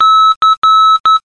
Marker Beacons